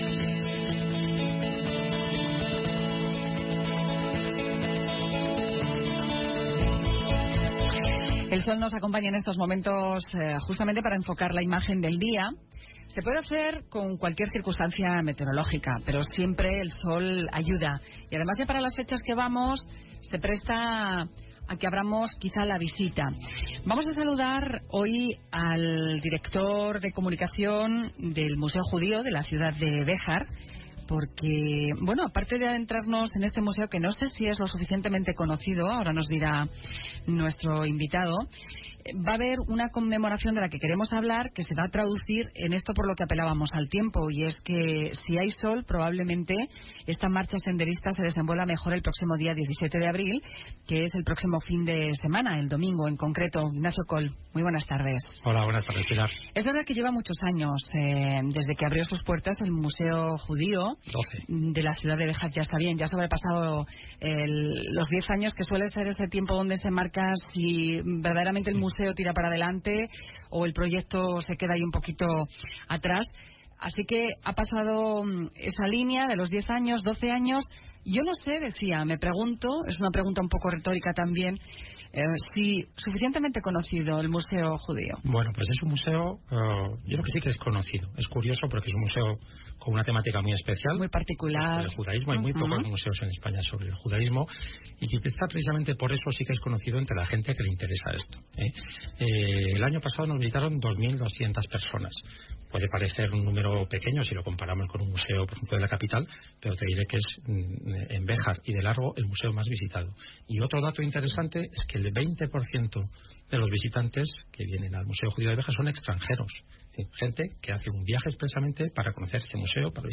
Entrevista sobre el Museo Judío de Béjar en Onda Cero Salamanca